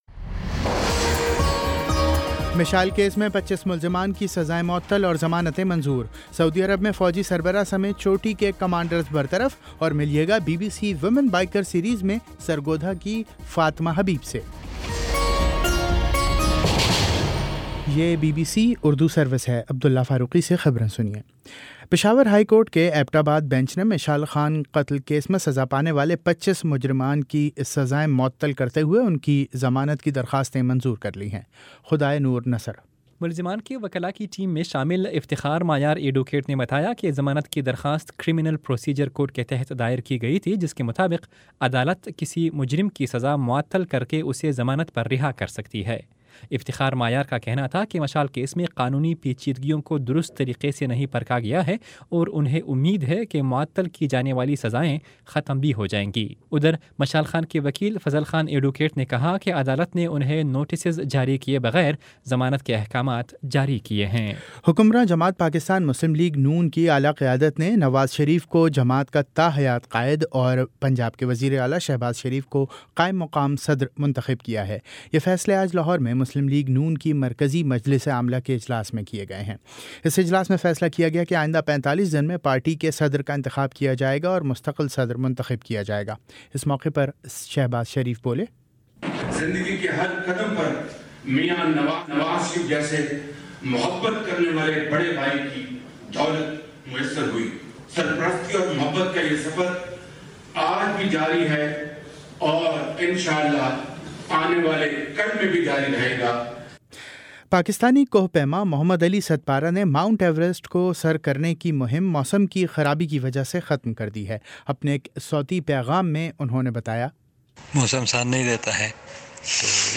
دس منٹ کا نیوز بُلیٹن روزانہ پاکستانی وقت کے مطابق شام 5 بجے، 6 بجے اور پھر 7 بجے۔